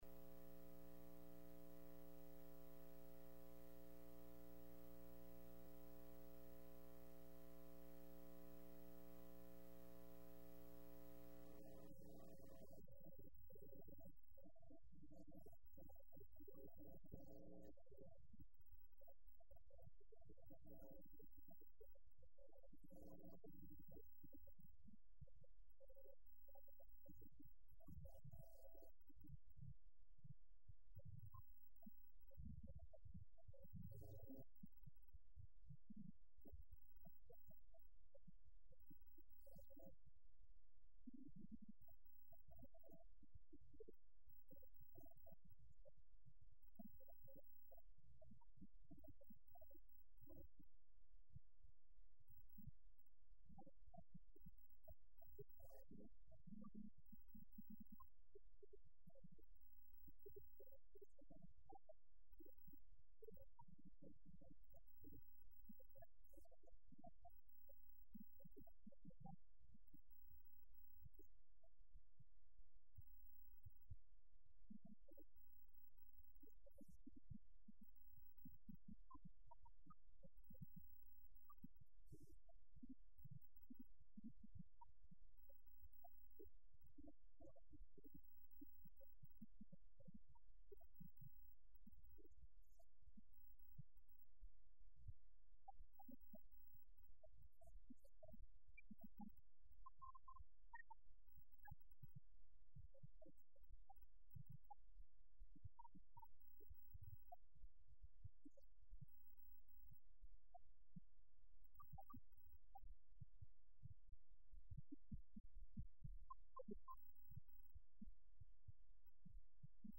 09/21/11 Wednesday Evening